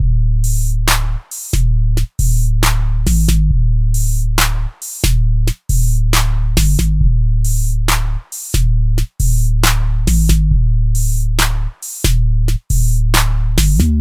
描述：典型的金属鼓是由汤姆鼓、小鼓和大鼓组成的。
Tag: 150 bpm Heavy Metal Loops Drum Loops 1.08 MB wav Key : Unknown